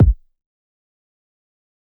kits/Southside/Kicks/PS - BFE.wav at main